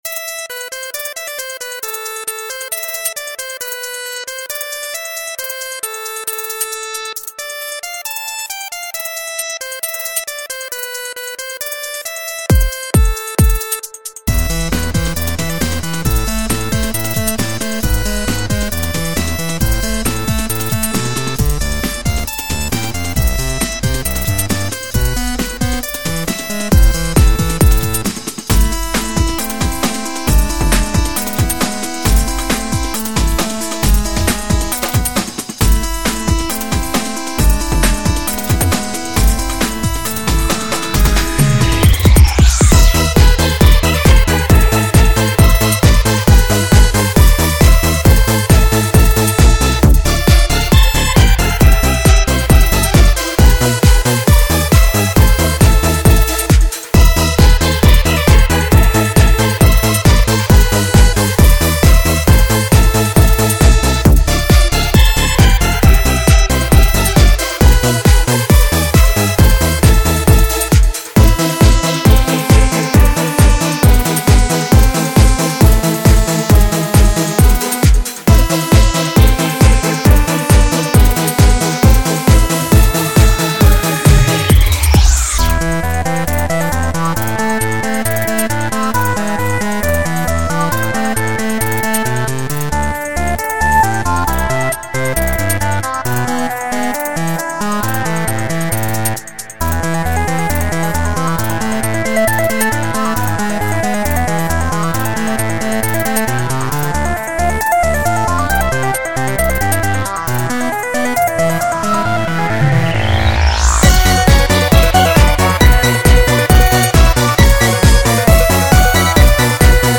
Techno Remix)file